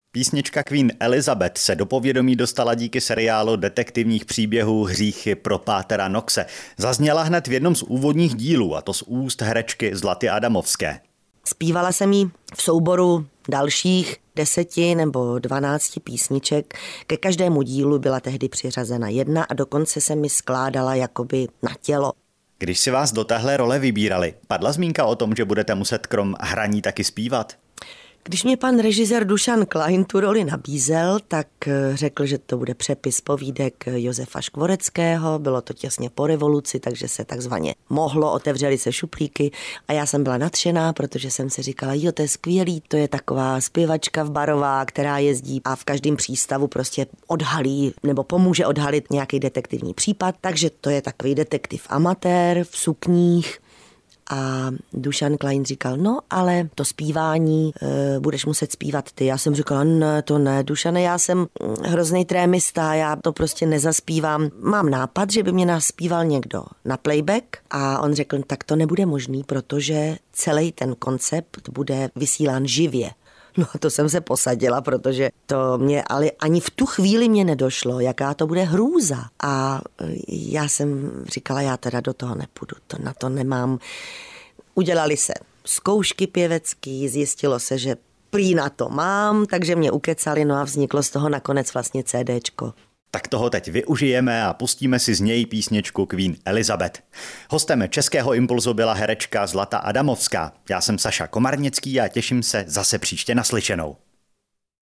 Český Impuls – rozhovor